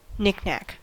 Ääntäminen
Vaihtoehtoiset kirjoitusmuodot knick-knack Synonyymit chachka Ääntäminen US : IPA : [ˈnɪkˌ.næk] Haettu sana löytyi näillä lähdekielillä: englanti Käännöksiä ei löytynyt valitulle kohdekielelle.